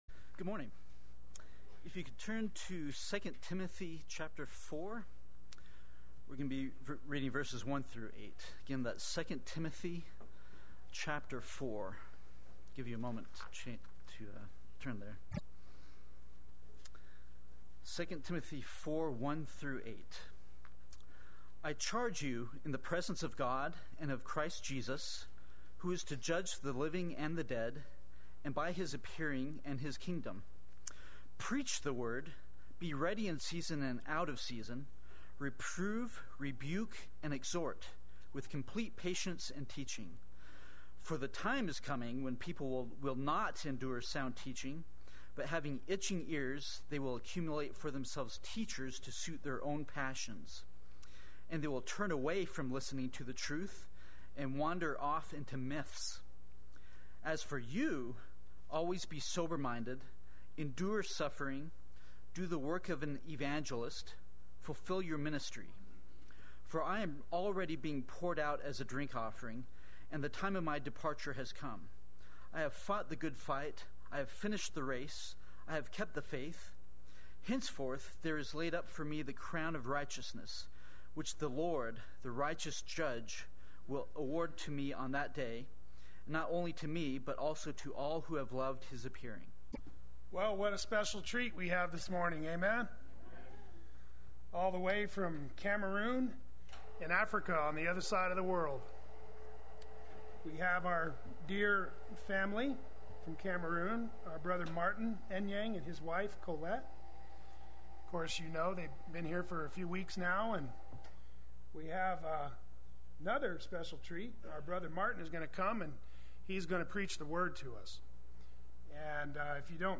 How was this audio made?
Preach the Word Sunday Worship